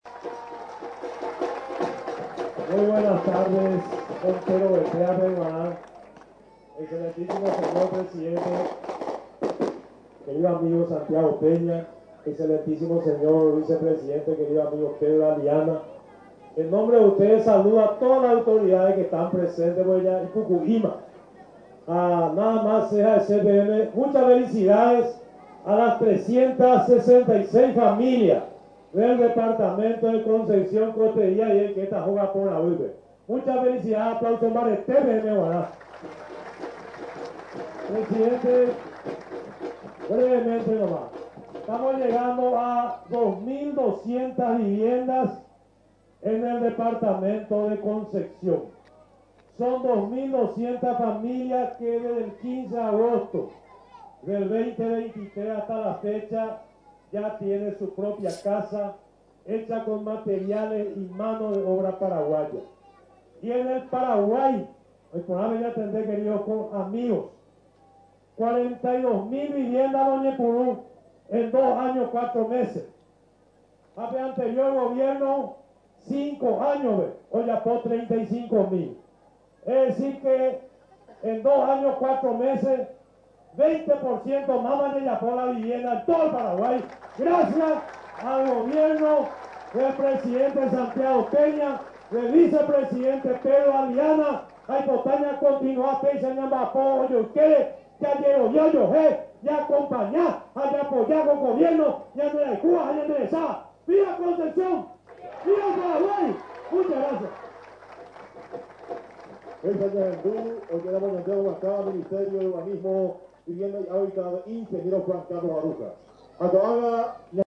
Unas 366 familias recibieron las llaves de sus nuevas casas, destacó este viernes durante la jornada de Gobierno, el ministro de Urbanismo, Vivienda y Hábitat, Juan Carlos Baruja.
Por su parte, el presidente de la República, Santiago Peña destacó las tareas realizadas durante la jornada de Gobierno en el departamento de Concepción.